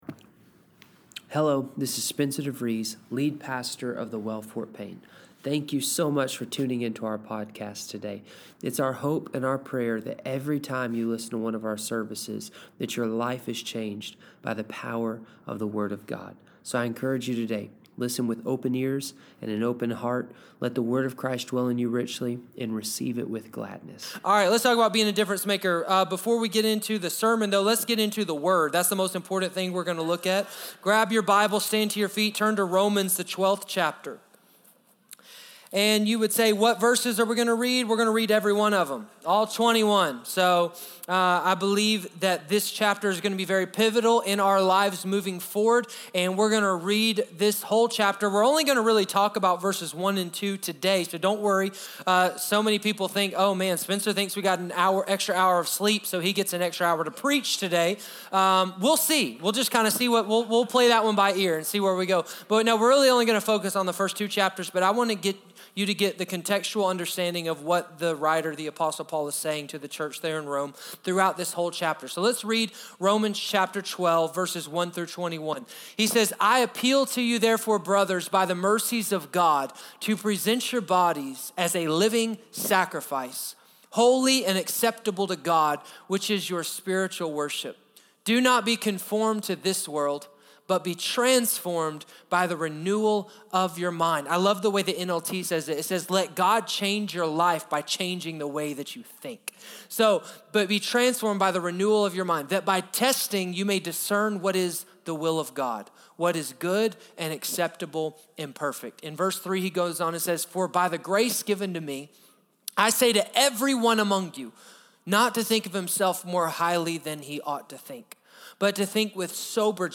Sermons | The Well Fort Payne